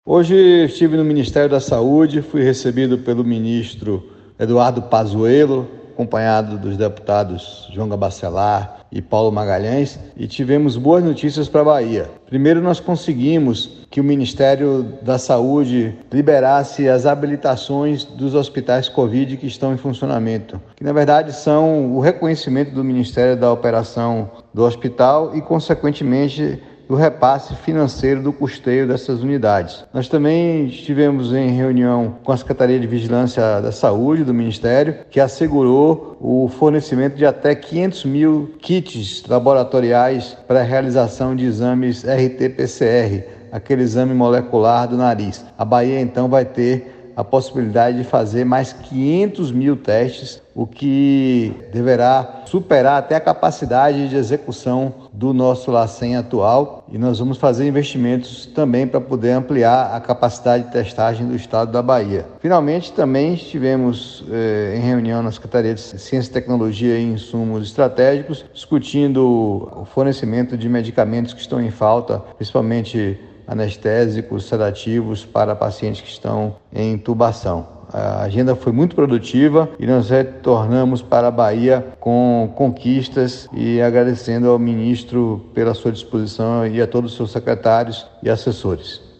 Ouça nota do secretario: